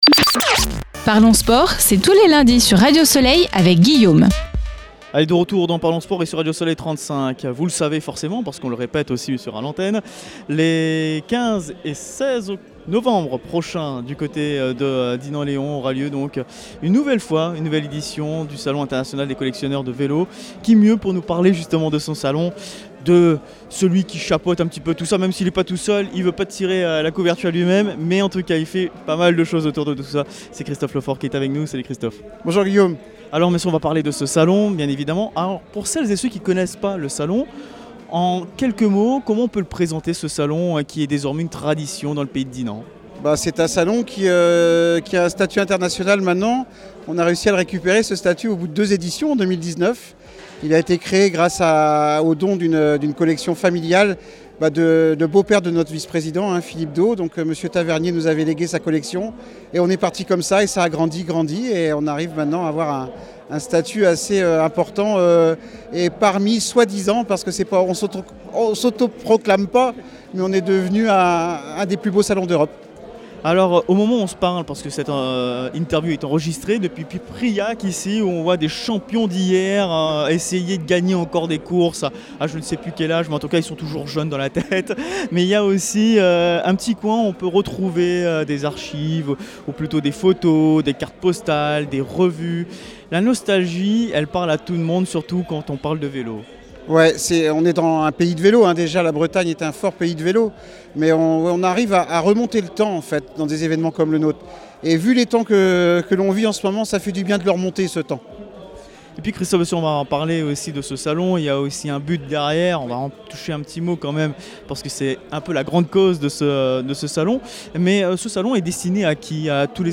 Radio Soleil 35 en direct du Salon des Collectionneurs Vélo à Dinan-Léhon (22) - Radio Soleil 35
Radio Soleil 35, en compagnie de MémoireSport, était notamment en direct ce samedi 15 novembre depuis le Salon.